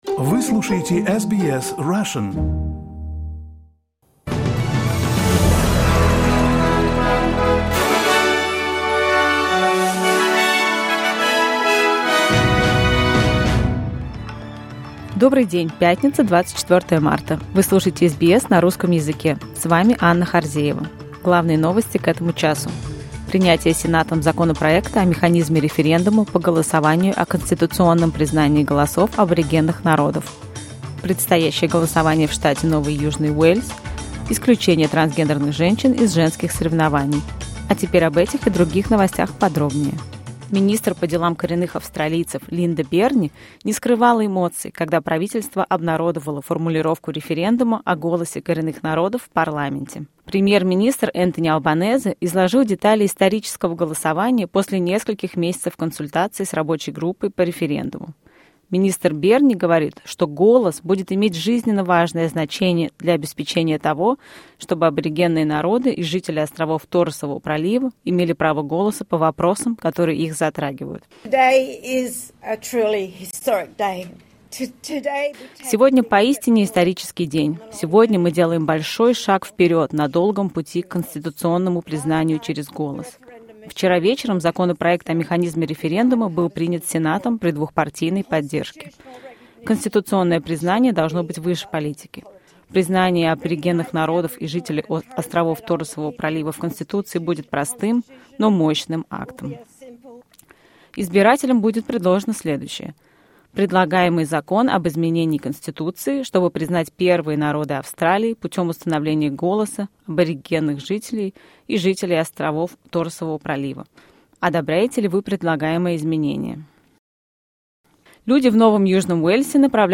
SBS news in Russian — 24.03.2023